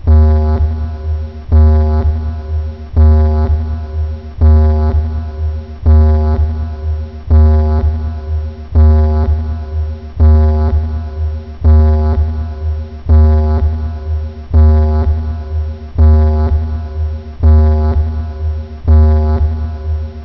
Slide1_lobeep1_echo.wav